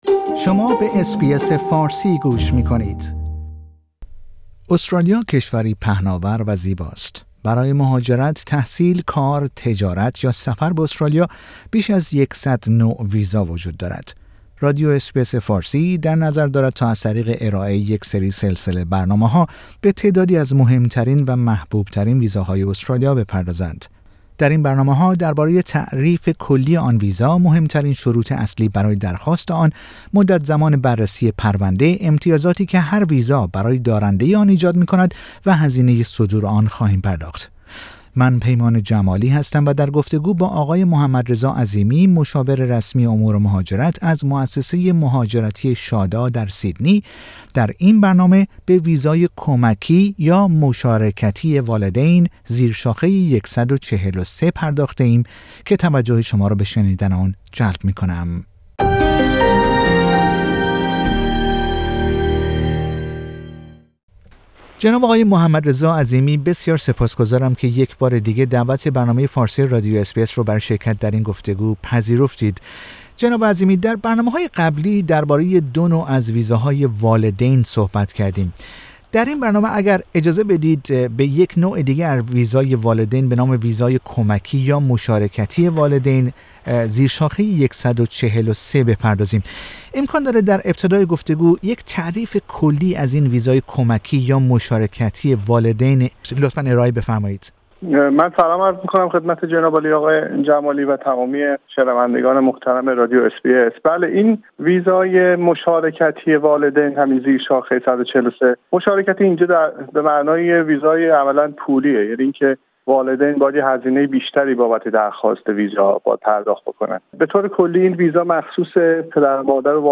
در این برنامه ها درباره تعریف کلی آن ویزا، مهم ترین شروط اصلی برای درخواست آن، مدت زمان بررسی پرونده، امتیازاتی که هر ویزا برای دارنده آن ایجاد می کند و هزینه صدور آن خواهیم پرداخت. در این برنامه در گفتگو